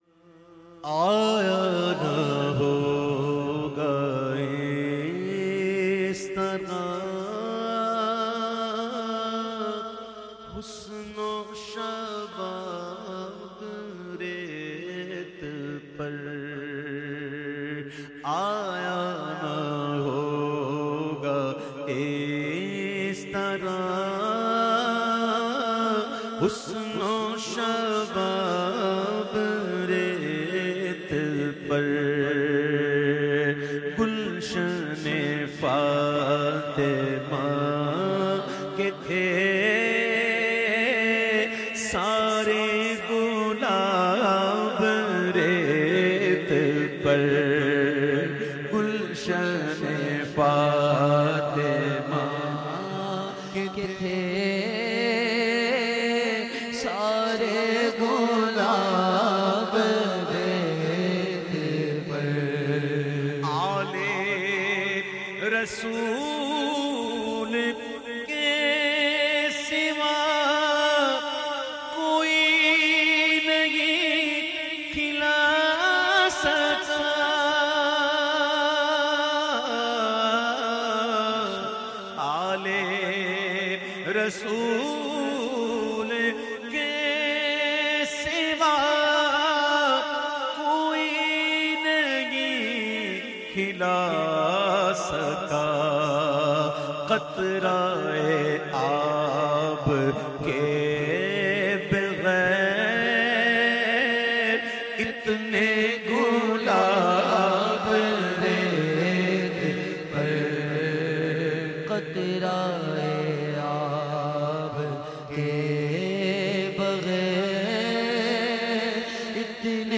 This Manqabat related to Hazrat e Imam e Hussain
منقبت